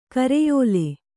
♪ kareyōle